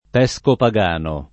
[ p HS kopa g# no ]